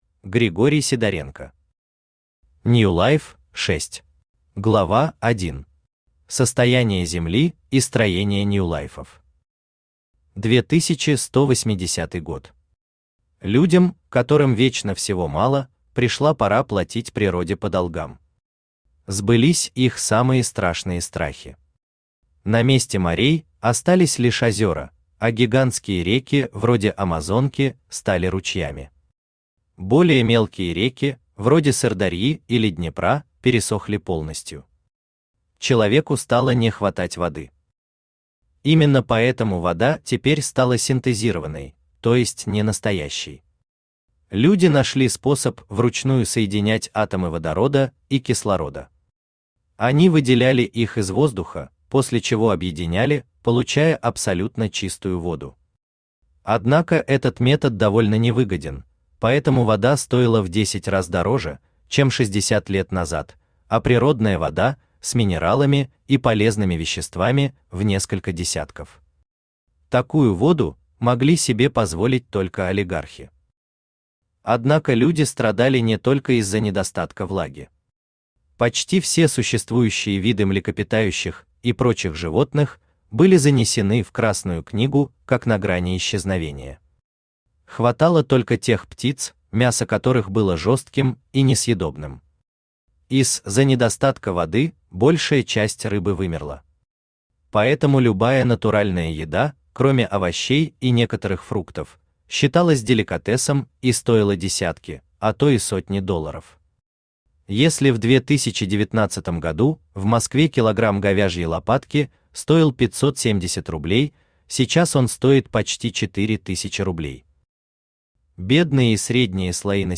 Аудиокнига Ньюлайф – 6 | Библиотека аудиокниг
Aудиокнига Ньюлайф – 6 Автор Григорий Михайлович Сидоренко Читает аудиокнигу Авточтец ЛитРес.